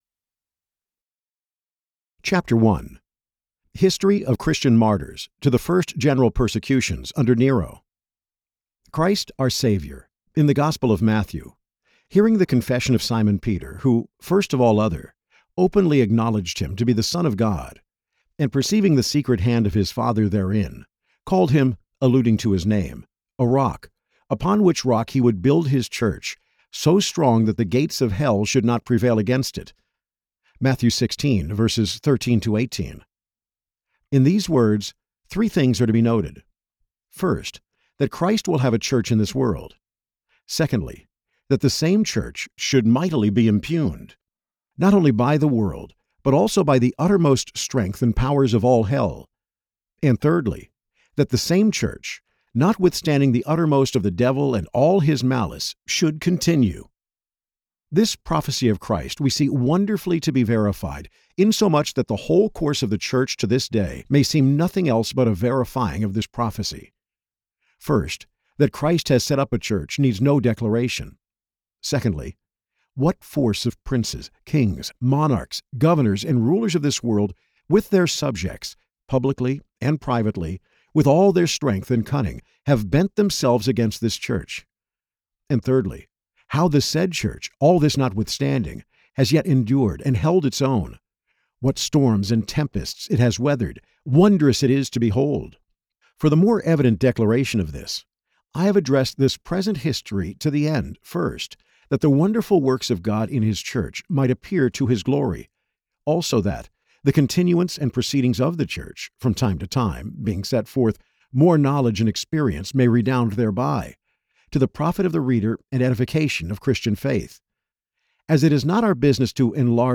Audiobook Download, 10 hours 20 minutes